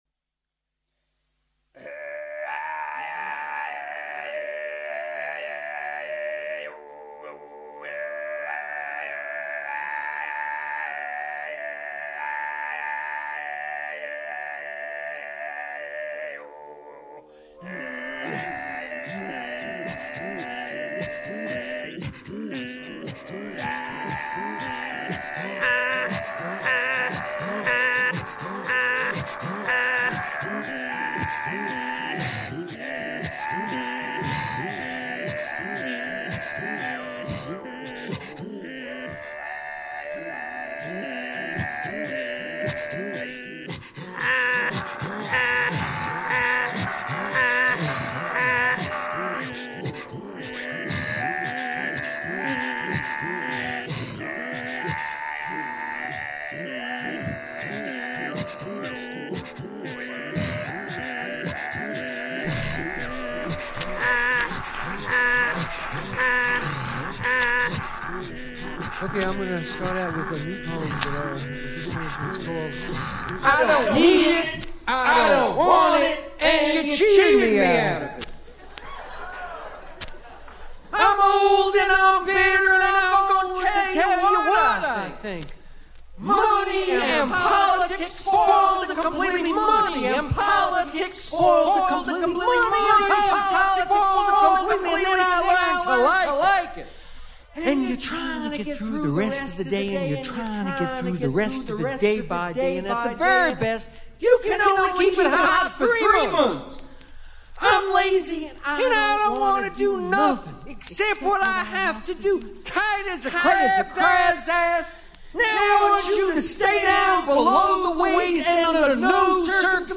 Voici les premiers essais d'expérimentation sonore, qui prendra bientôt une couleur davantage personnelle à mersure que je trouverai le temps de me faire aller la glotte et la carte de son au rythme des hurlements à la lune du grand gourou de la verve, j'ai nommé Claude Gauvreau. En attendant, je vous propose deux petits mix qui ont été présentés lors de ma dernière intervention 'pataphysique.
intervention 1 : variations sur chants de gorge et d'âme (en format mp3)